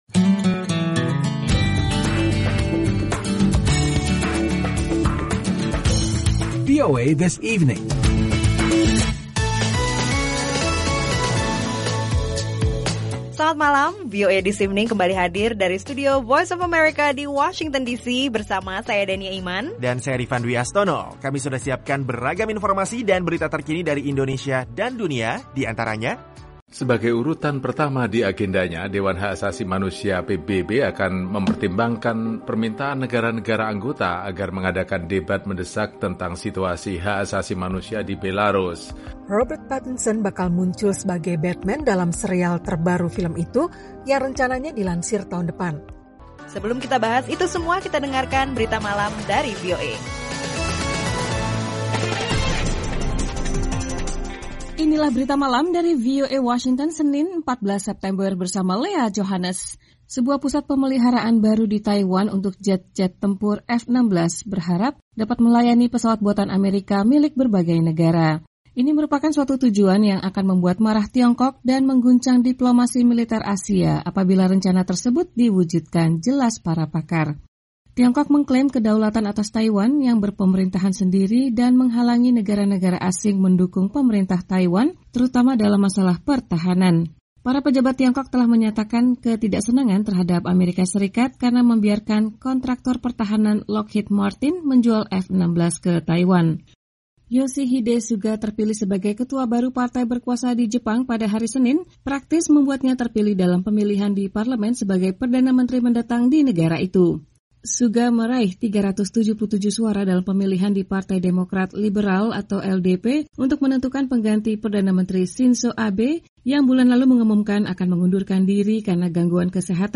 Akhiri kesibukan hari kerja Anda dengan rangkuman berita terpenting dan informasi menarik yang memperkaya wawasan Anda dalam VOA This Evening.